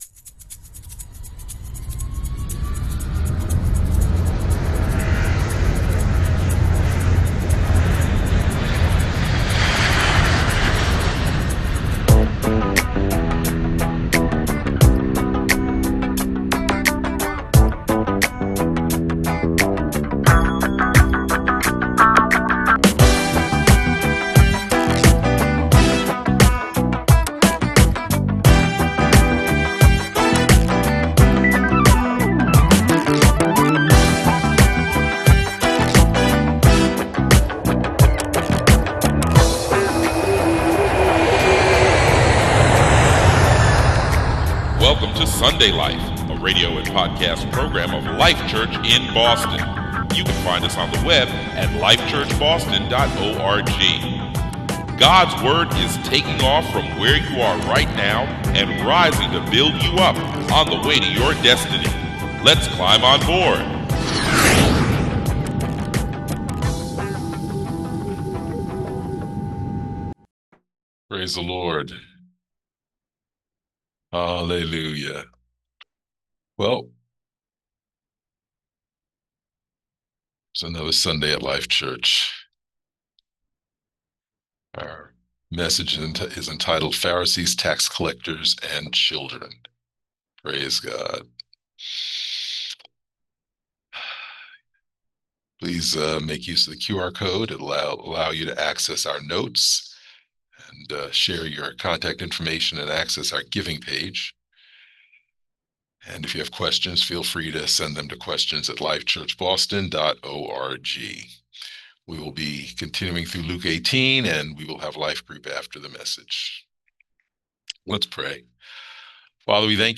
Notes for 08/10/2025 Sermon - Life Church